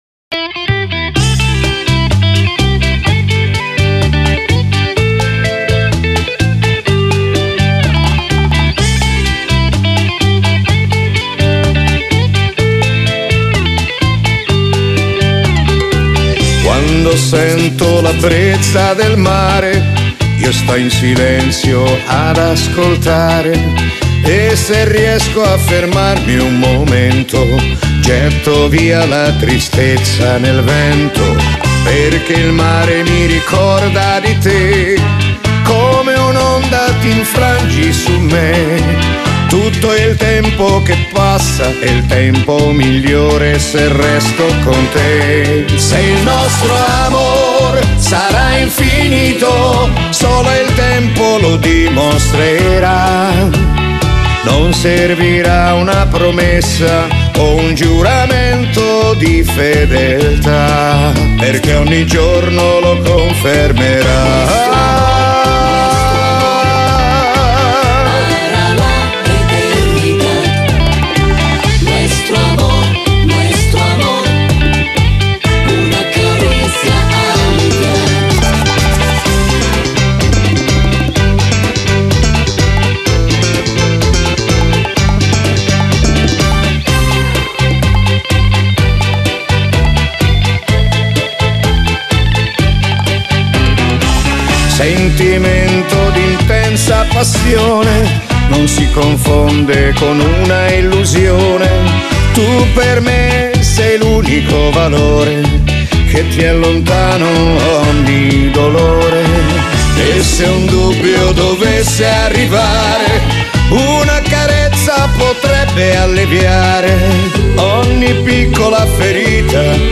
Genere: Bachata